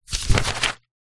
Звуки цензуры
Звук мятого клочка бумаги для вырезания матерных слов из видео